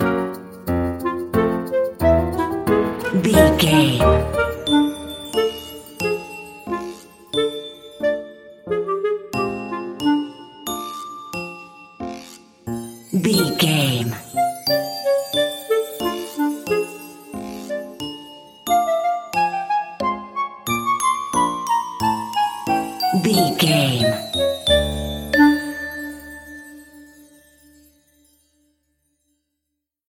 Music
Uplifting
Aeolian/Minor
D
Slow
flute
oboe
piano
percussion
silly
circus
goofy
comical
cheerful
perky
Light hearted
quirky